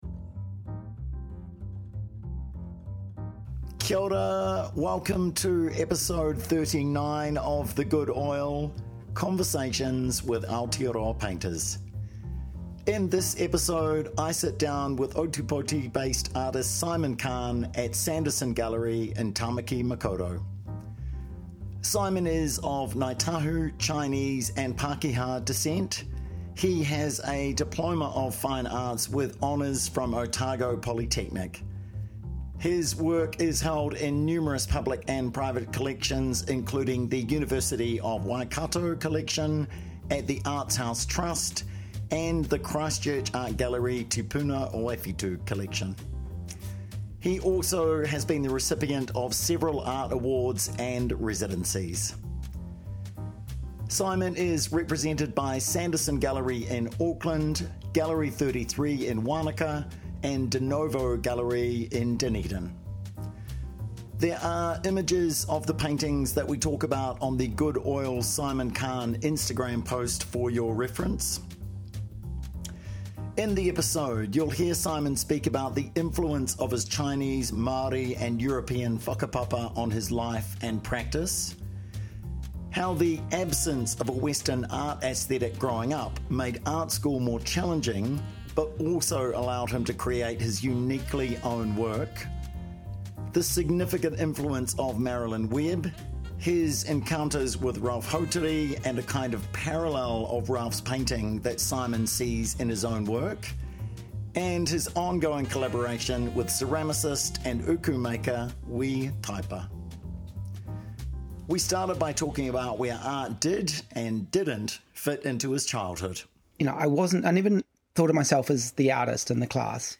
The Good Oil is dedicated to long form conversations with Aotearoa / New Zealand painters about their lives and practices.